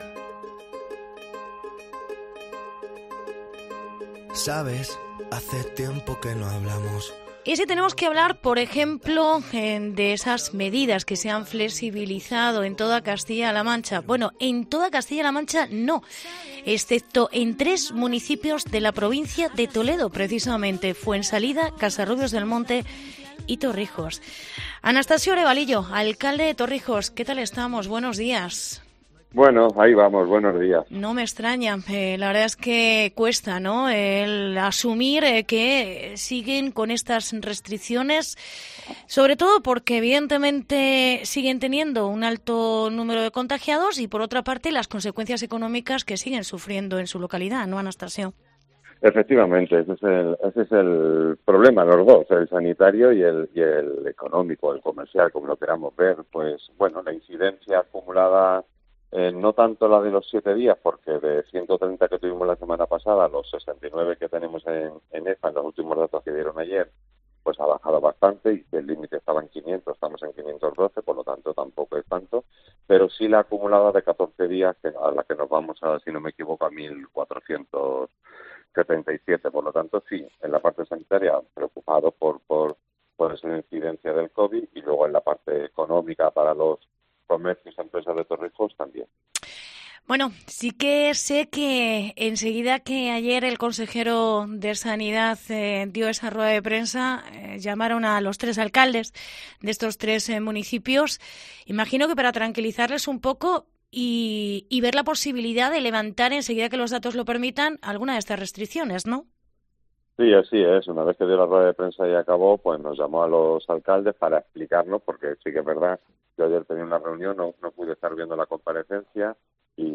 Entrevista con Anastasio Arevalillo. Alcalde de Torrijos